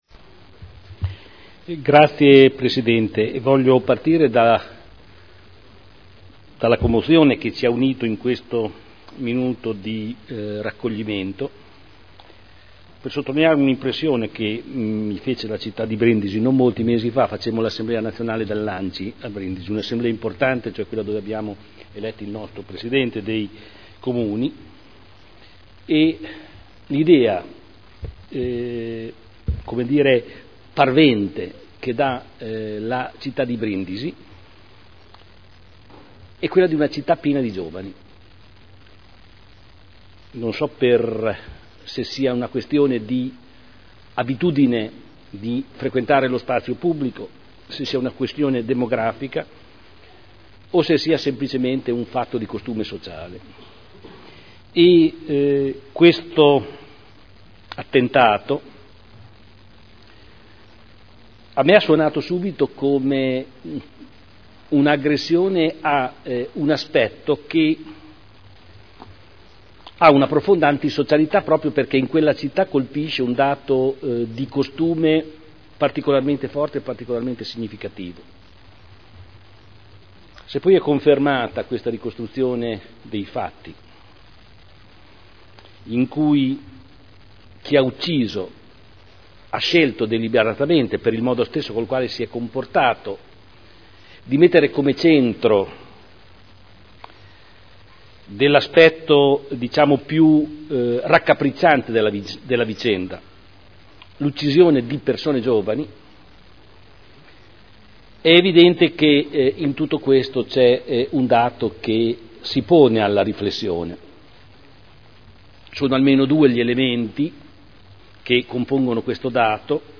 Seduta del 21 maggio Comunicazioni sul terremoto che ha investito la provincia di Modena